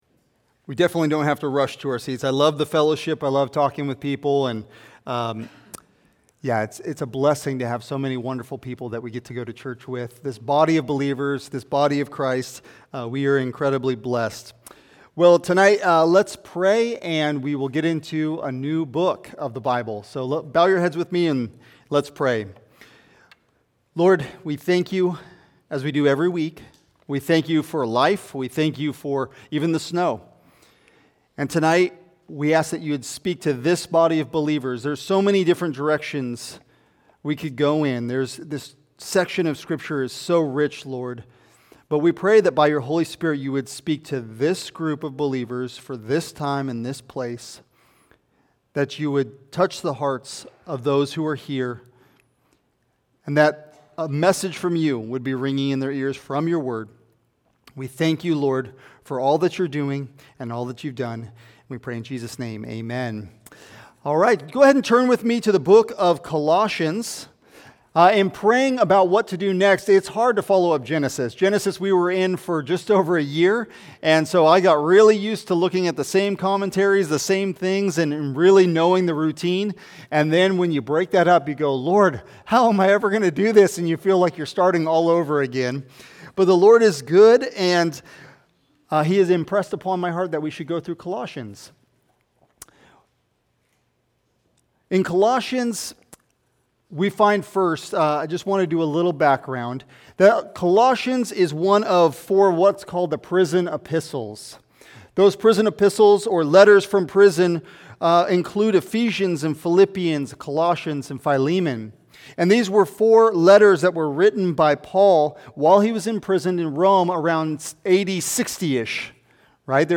Sermons | Heritage Bible Church of Tri Cities